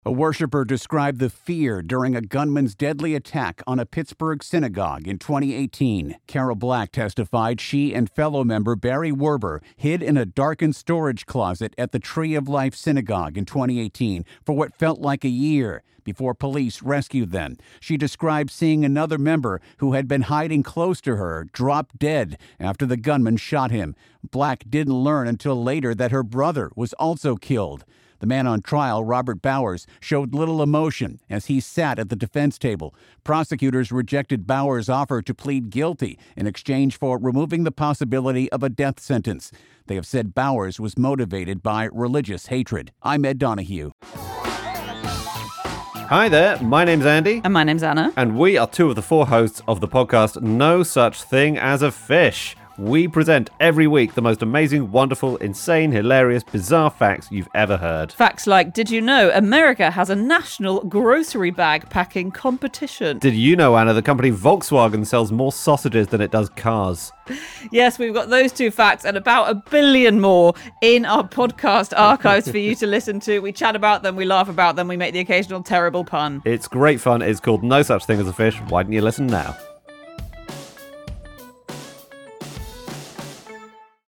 'Felt like a year': Worshipper describes fear during gunman's deadly attack on Pittsburgh synagogue